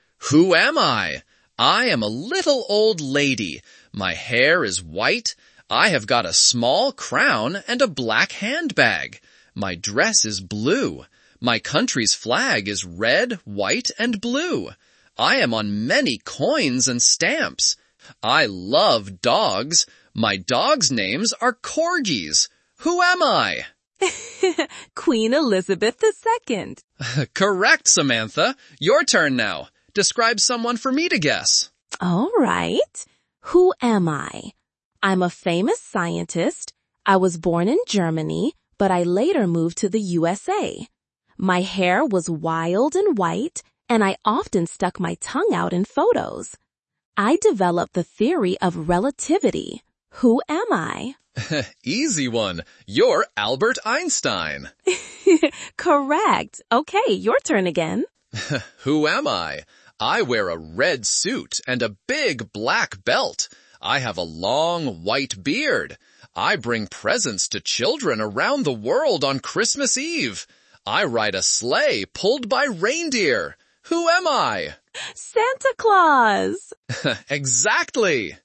A simple demo of Podcast generator produced with Google Gemini